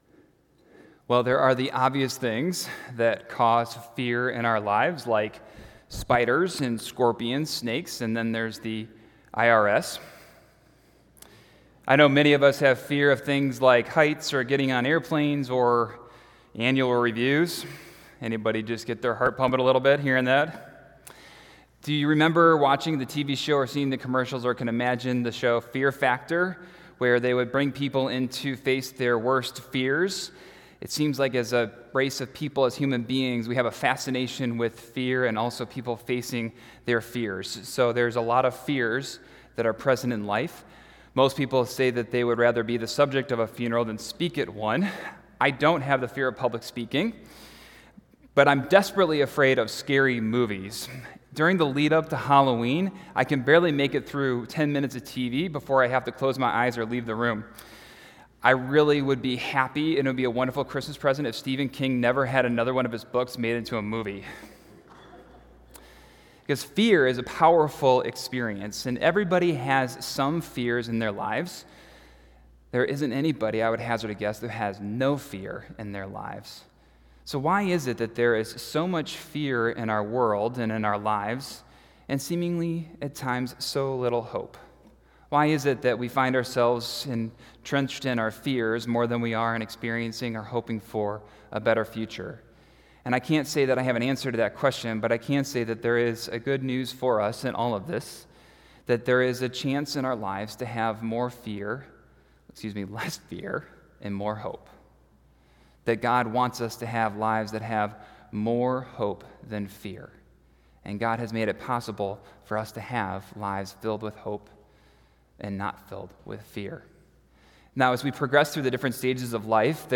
December 24, 2018 – Christmas Eve Sermon – Park Ridge Presbyterian Church